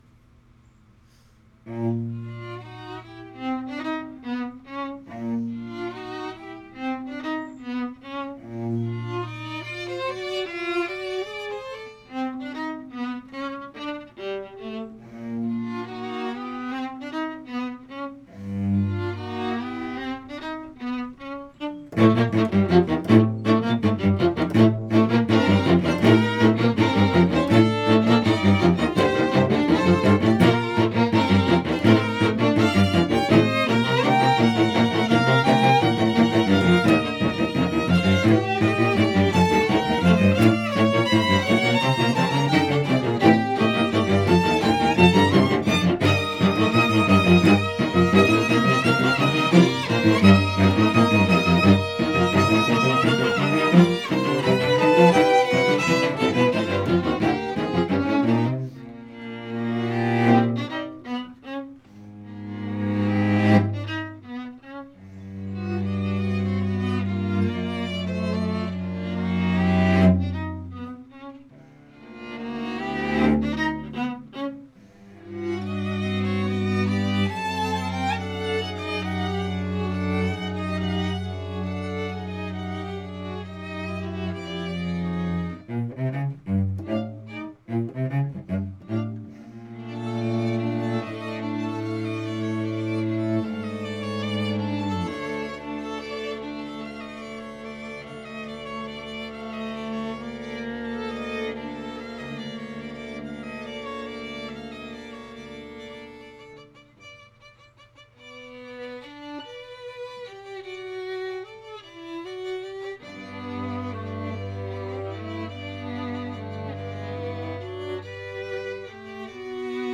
2:00 PM on July 20, 2014, "Music with a View"
Allegro non troppo